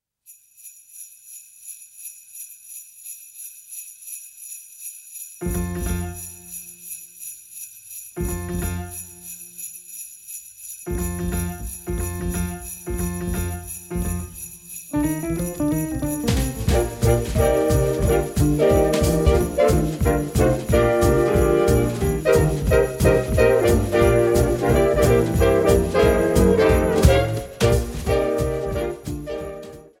voc